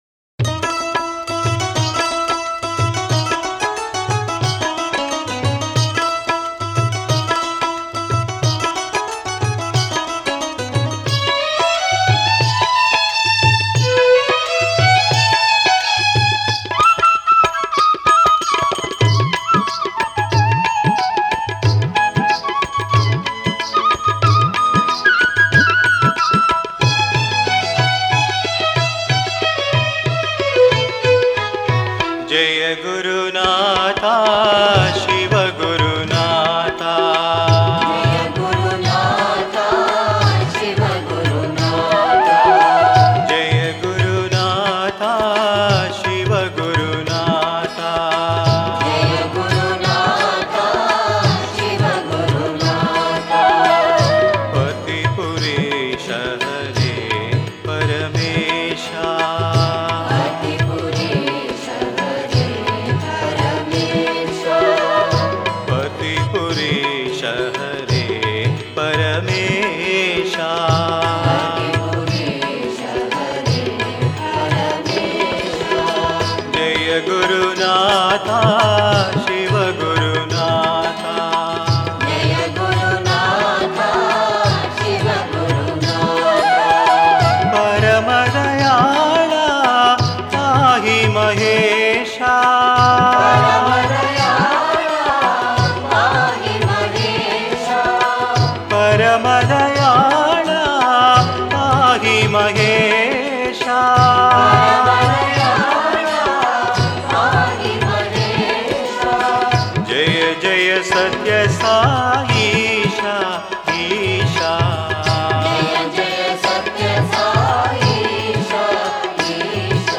Home | Guru Bhajans | 23 JAYA GURUNATHA SIVA GURUNATHA